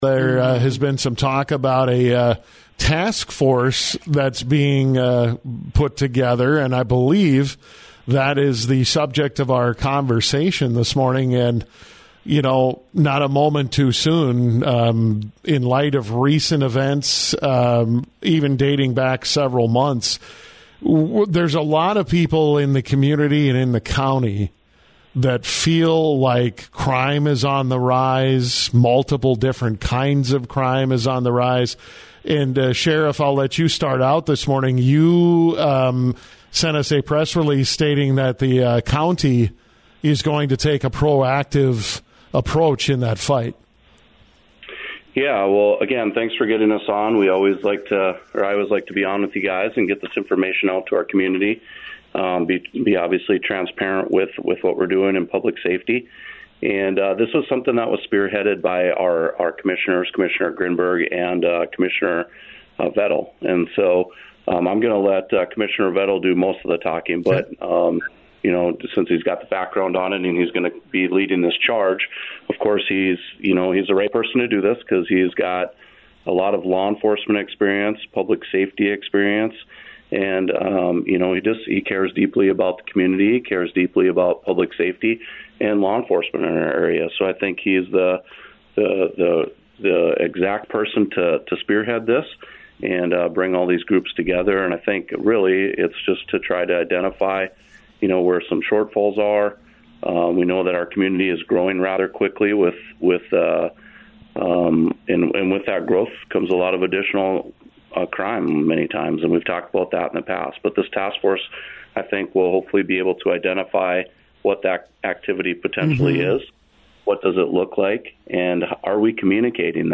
LISTEN: Sheriff Jesse Jahner and Commissioner Joel Vettel on “The Coffee Club”